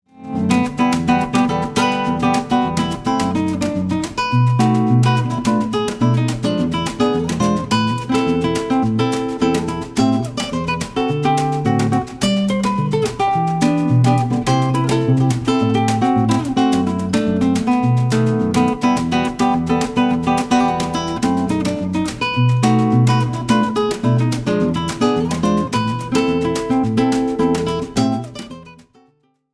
Guitar
Percussions